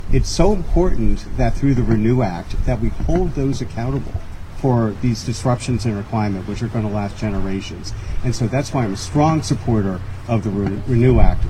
The Chesapeake Climate Action Network held a rally in Annapolis Wednesday about the reintroduction of the RENEW Act bill that failed last year.